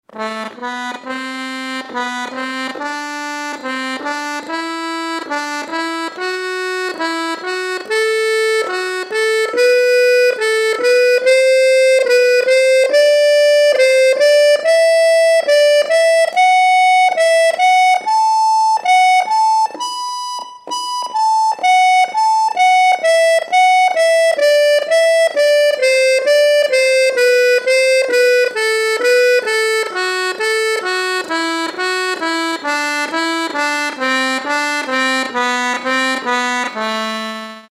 Accordeon diatonique et Musiques Traditionnelles
GAMMES SYNTHETIQUES = gammes incomplétes, les notes en sens inverse du soufflet ne sont pas jouées.
3 - Gamme synthétique de Sol lent par 3 en tiré par 3
gammesynthetiquesoltpar3lent.mp3